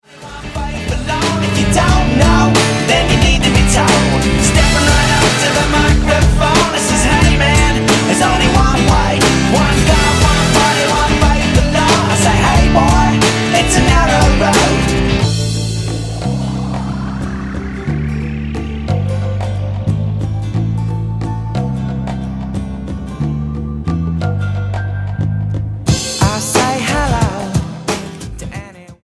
STYLE: Rock
Timelessly catchy pop-rock.